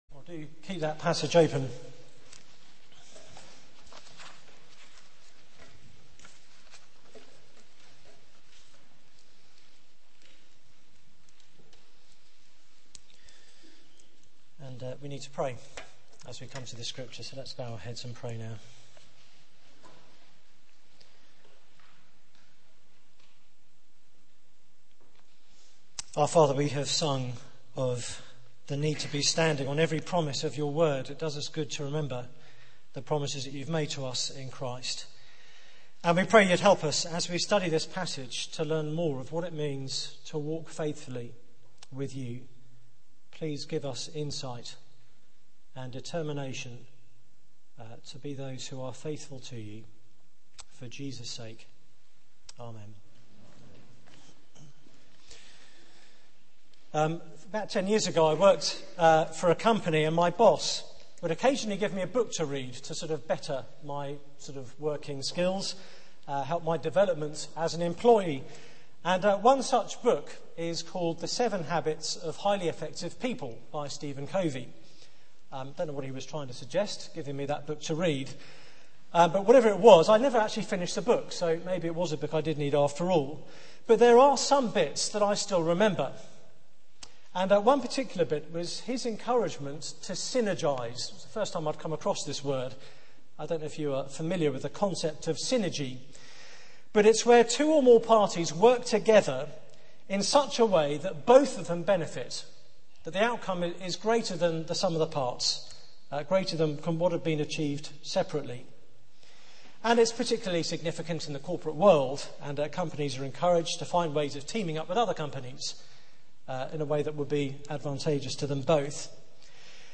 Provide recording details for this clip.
Media for 6:30pm Service on Sun 29th Nov 2009 18:30 Speaker: Passage: Genesis 16 Series: The Gospel According To Abraham Theme: The mistake Sermon Search the media library There are recordings here going back several years.